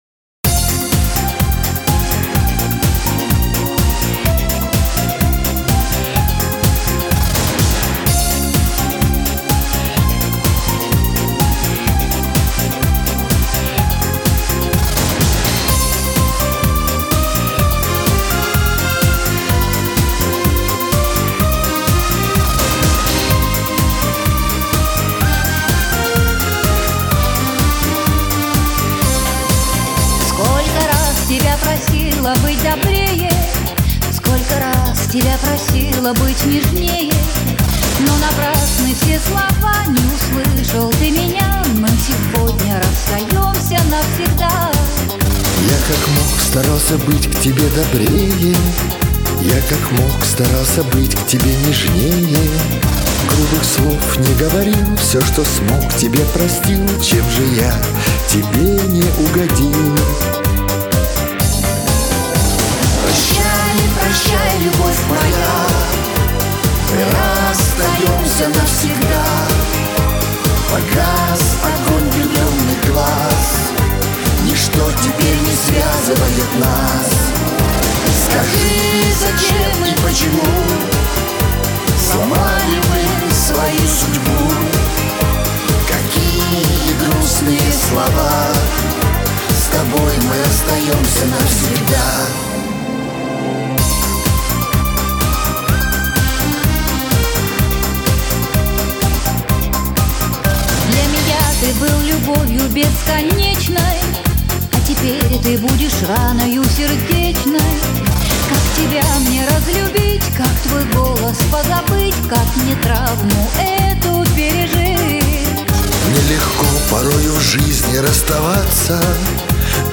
Дуэты
Жанр: Рор